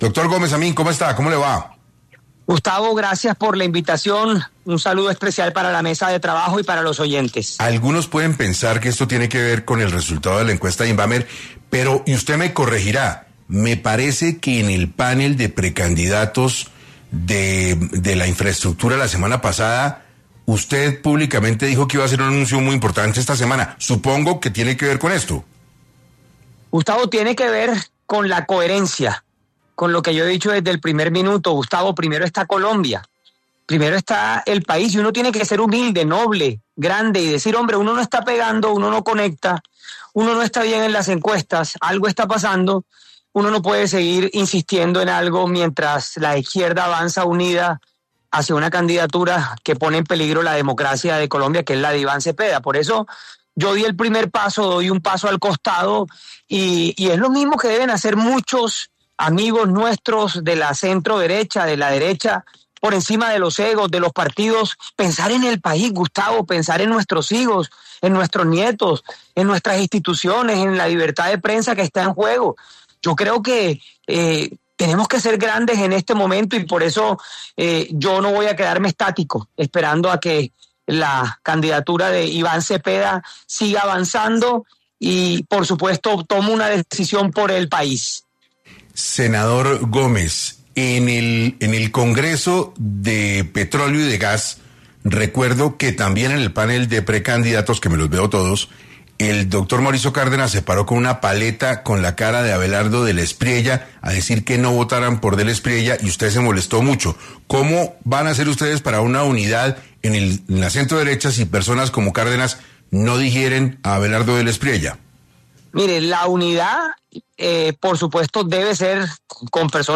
En entrevista con 6AM de Caracol Radio, el exprecandidato presidencial, dijo que: “Uno tiene que ser humilde, noble, grande, y decir, hombre, uno no está pegando, uno no conecta, uno no está bien en las encuestas, algo está pasando.